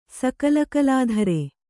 ♪ sakala kalādhare